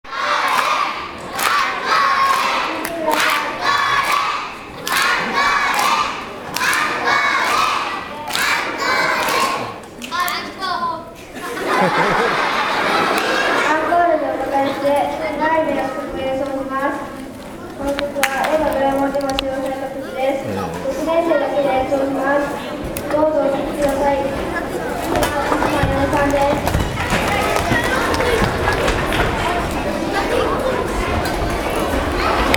日頃の練習の成果を発揮して体育館に美しい音色を響かせるとともに,趣向こらしたパーフォーマンスを披露しました。